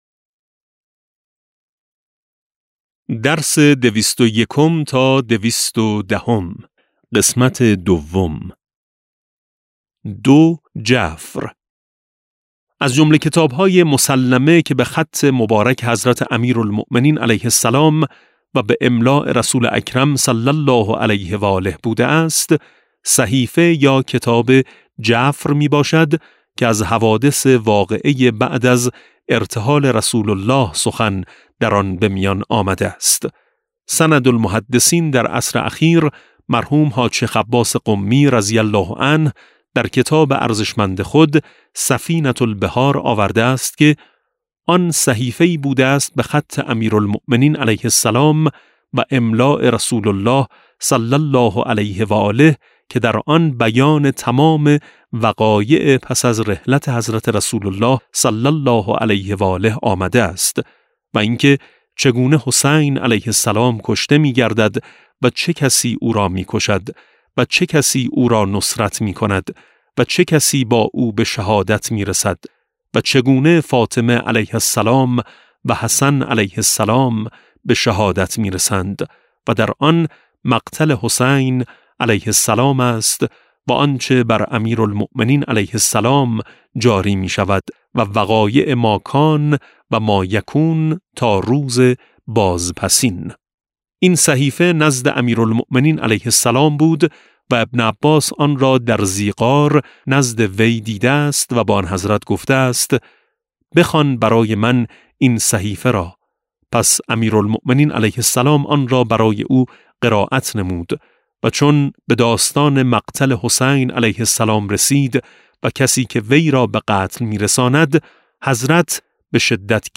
کتاب صوتی امام شناسی ج14 - جلسه10